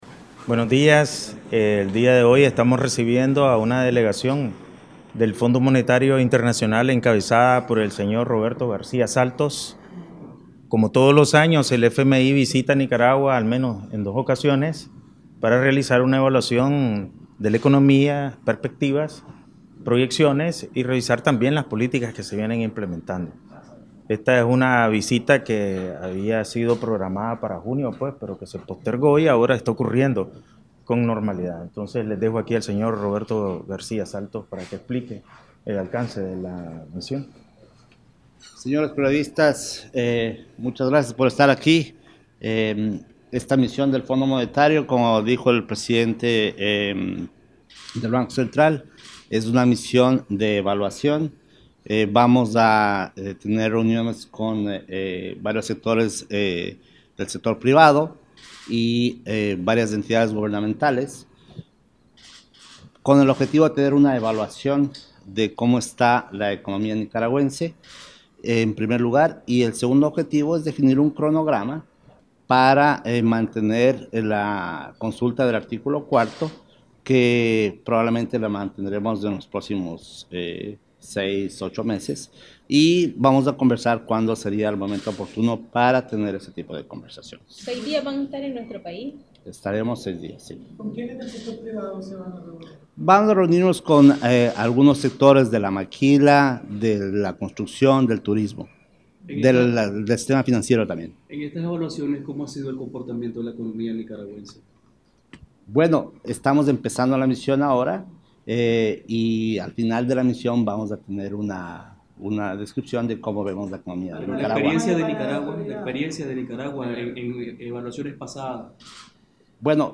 Audio Conferencia de Prensa Conjunta